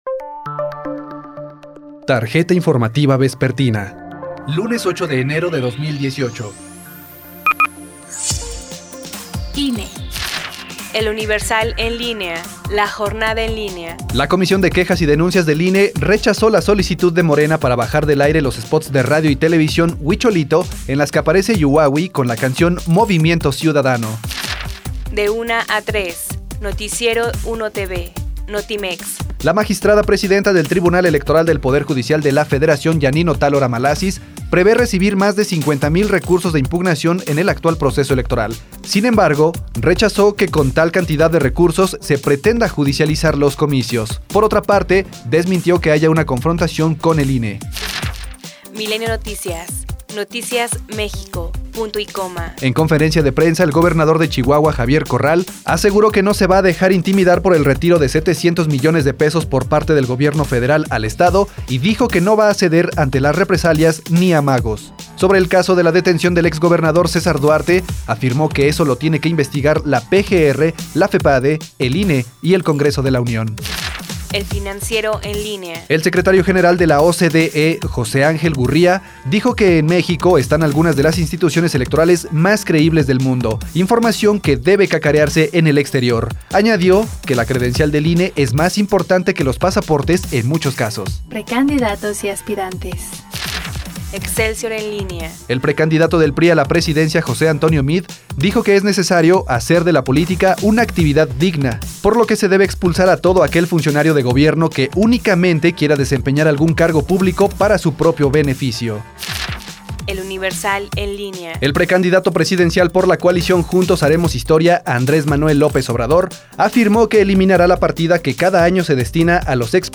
Corte informativo vespertino, 8 de enero de 2018